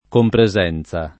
compresenza [ kompre @$ n Z a ]